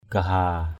/ɡ͡ɣa-ha:/ (cv.) katigaha kt{gh%
gaha.mp3